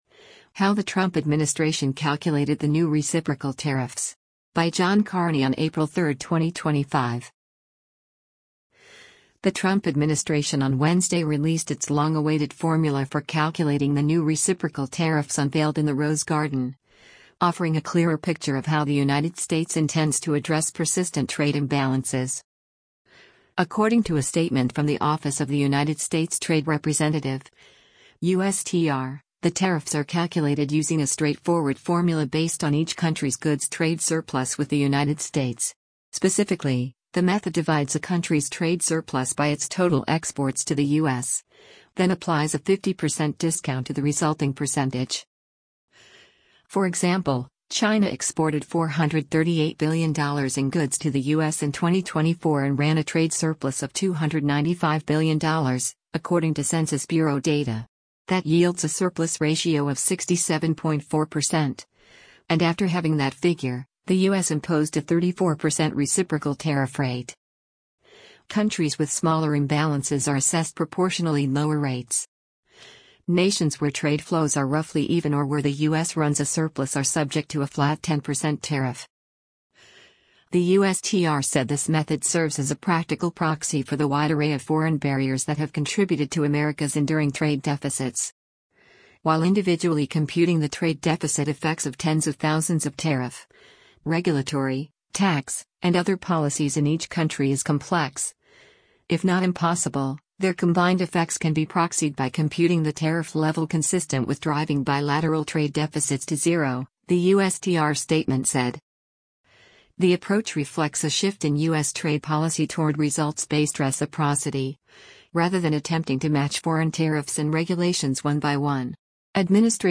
US President Donald Trump delivers remarks on reciprocal tariffs as US Secretary of Commer